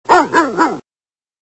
Categoria Animali